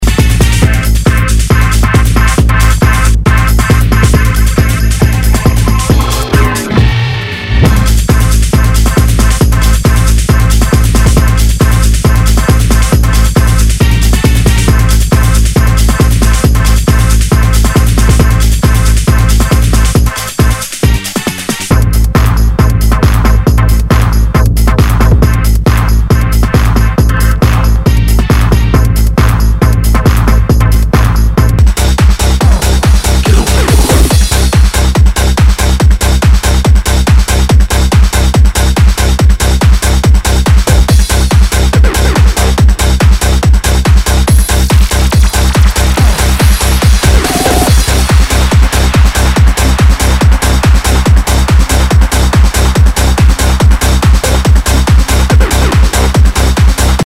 HOUSE/TECHNO/ELECTRO
ナイス！ハード・テクノ！